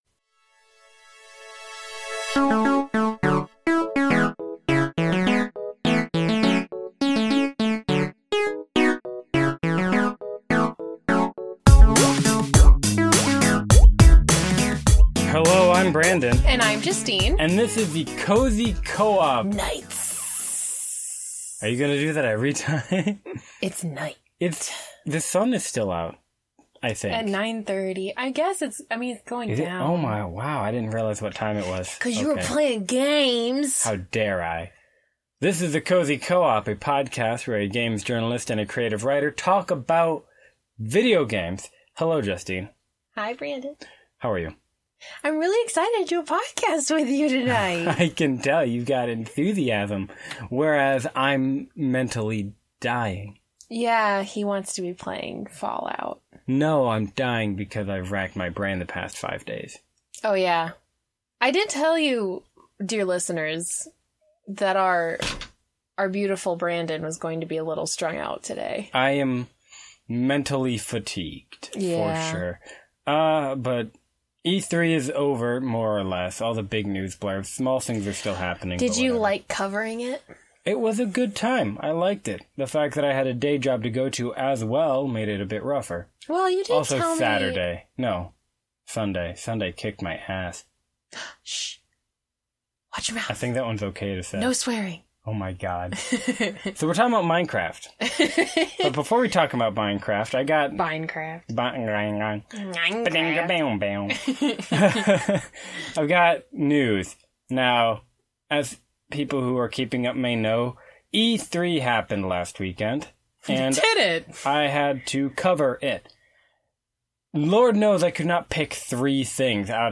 The Cozy Co-op is a podcast where a games journalist and a creative writer talk about video games!